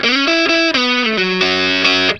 BLUESY3 FS90.wav